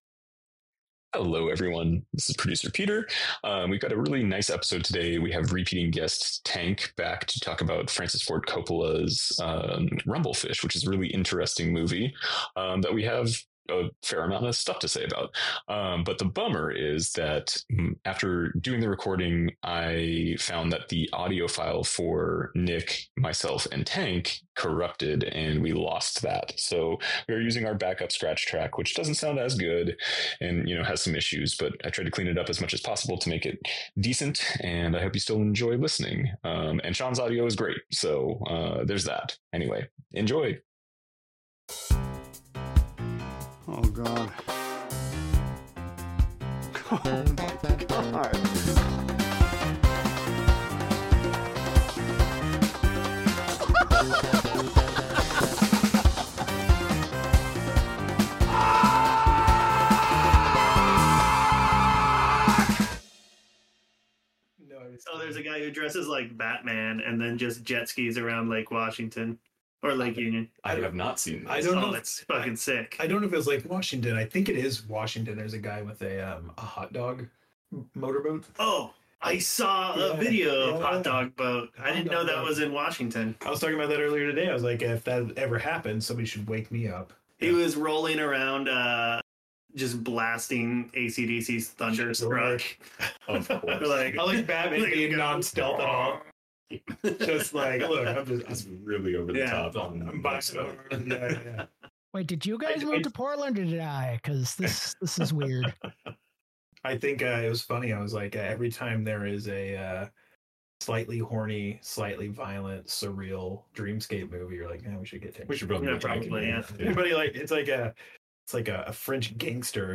*My apologies to any early listeners of this episode for the editing mishap regarding music playing over the first several minutes of the interview.